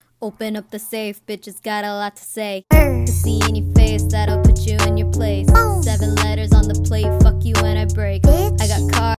boom 🤯 sound effects free download